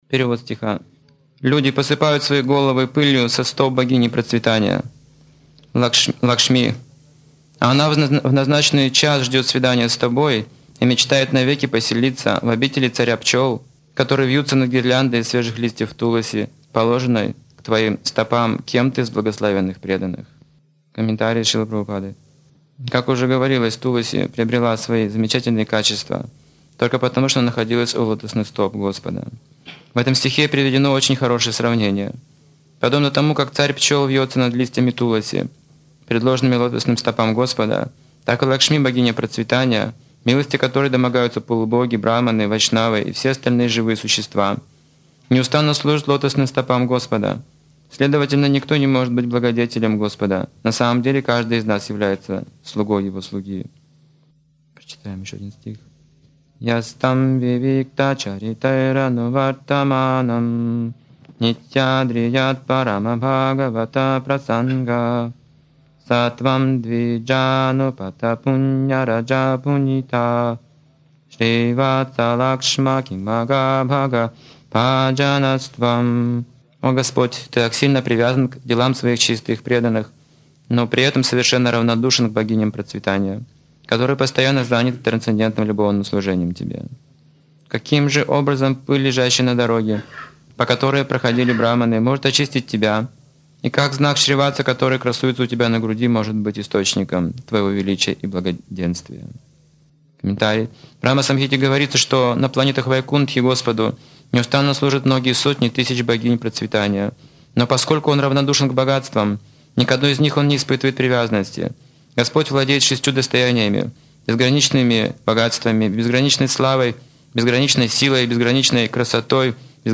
Темы, затронутые в лекции: Привязанность Лакшми к Господу 6 достояний Бога Богиня процветания Лакшми проклинает брахманов Духовная дружба 9 процессов преданного служения Особый замысел Кришны в изгнании Пандавов Для чего нужны преданные?